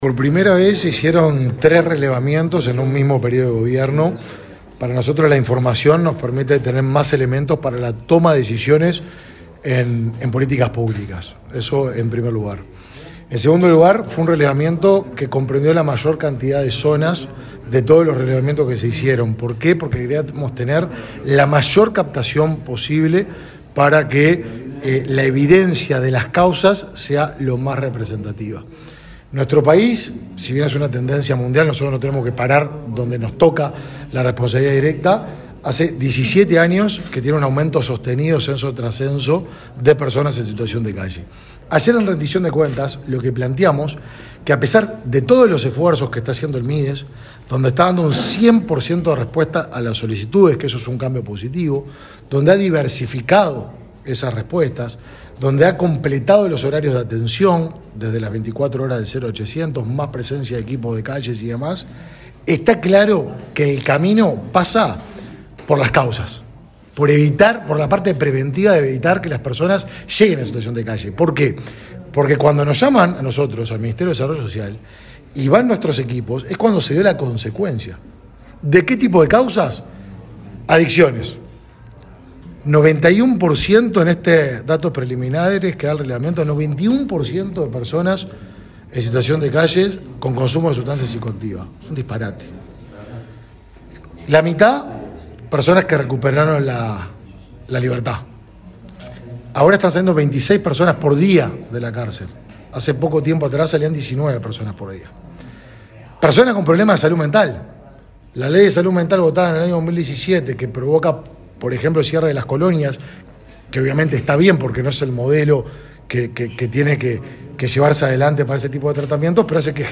Declaraciones a la prensa del ministro de Desarrollo Social, Martín Lema
Declaraciones a la prensa del ministro de Desarrollo Social, Martín Lema 03/08/2023 Compartir Facebook X Copiar enlace WhatsApp LinkedIn El Ministerio de Desarrollo Social suscribió, a través de la Dirección Nacional de Apoyo el Liberado, un acuerdo con AFE, a fin de que personas que recuperan la libertad realicen tareas de limpieza y desmalezamiento de áreas verdes en talleres del ente. El titular de la cartera, Martín Lema, firmó el convenio y luego dialogó con la prensa.